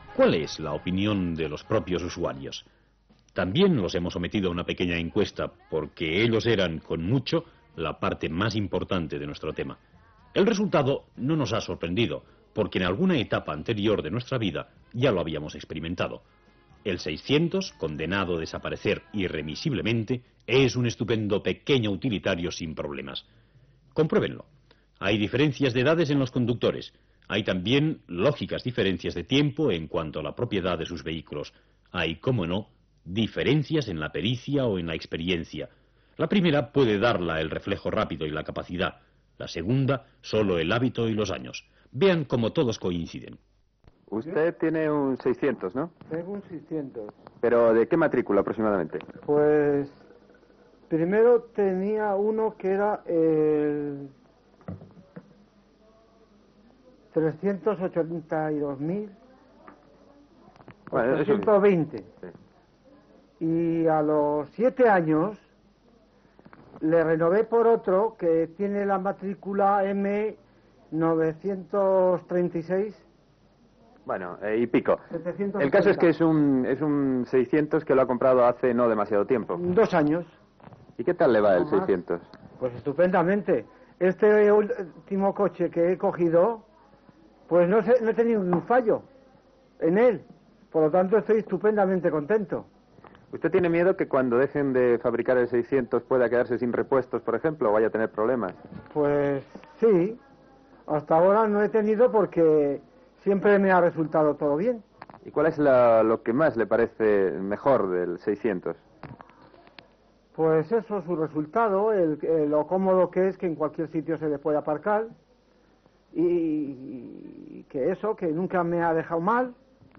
Entrevista a alguns propietaris del cotxe Seat 600 l'any que es va deixar de fabricar.
Informatiu
Extret del programa "El sonido de la historia", emès per Radio 5 Información el 22 de juny de 2013.